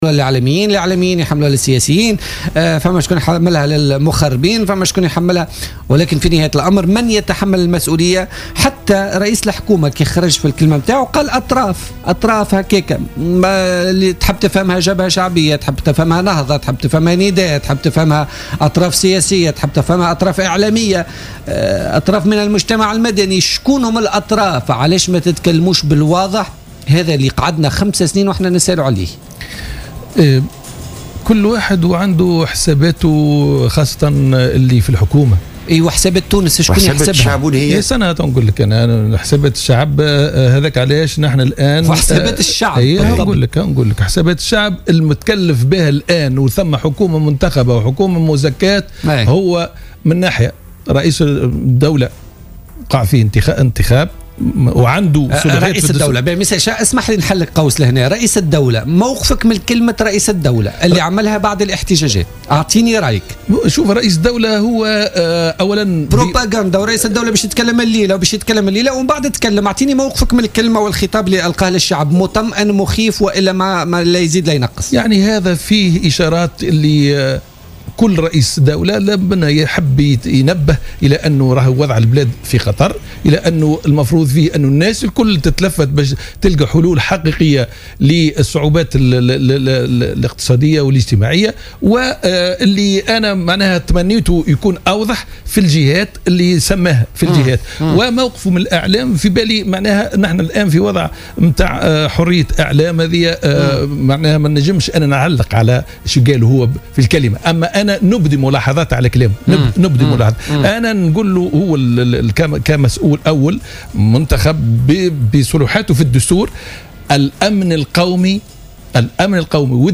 أكد النائب عن حركة النهضة وليد البناني ضيف بوليتيكا اليوم الإثنين 25 جانفي 2016 أنه كأحد أعضاء كتلة النهضة في البرلمان يساند الحكومة ولا يريدها أن تسقط مضيفا أنه يدعم هذه الحكومة برئاسة الحبيب الصيد نحو توسيع مجال الاستشارة والحوار من أجل ايجاد حلول فعلية لمشاكل البطالة والتشغيل الراهنة.